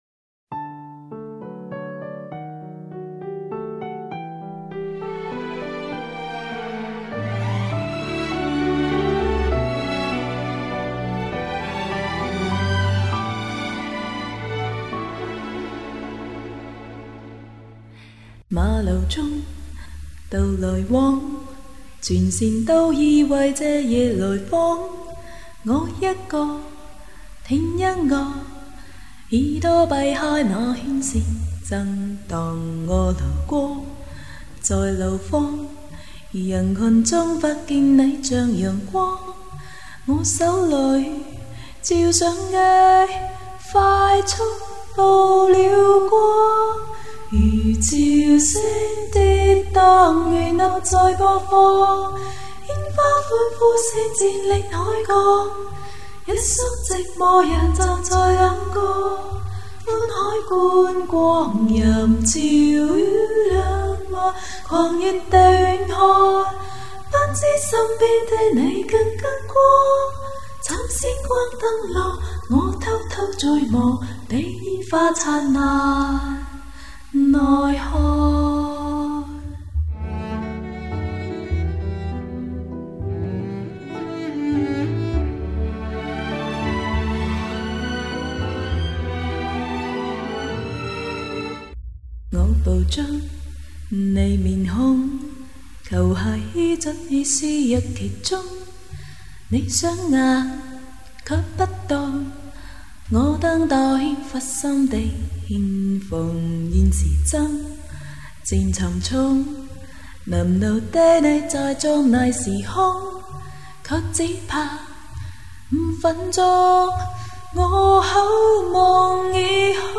自己做的消音伴奏，只能凑合用~！被呵出来的歌，当催眠曲还八错